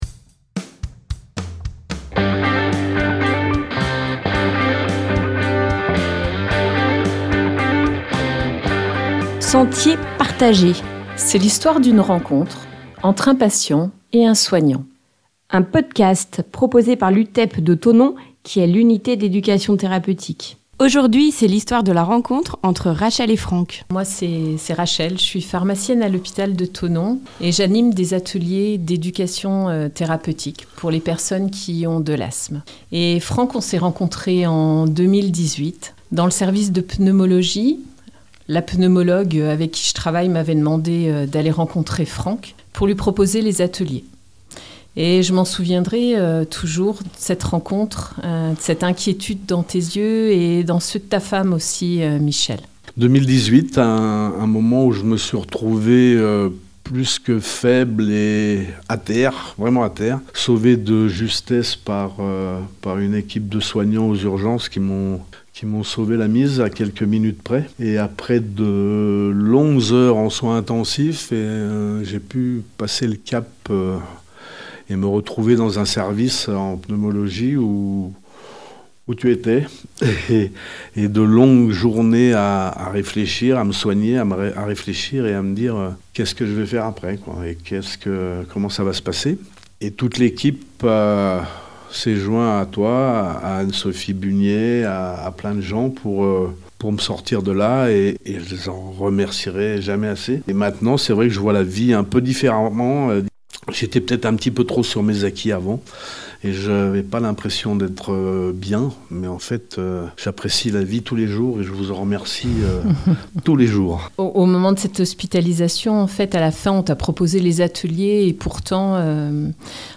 Sentiers Partagés, c’est une série de témoignages qui parle d’accueil, de rencontre et de coopération dans la santé.